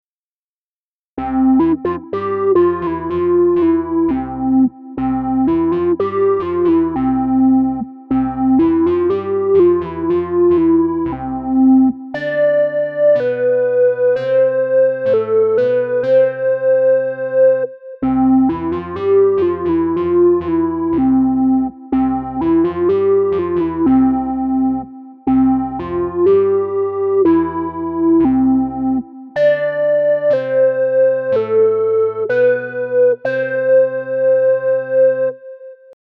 XILS4 Presets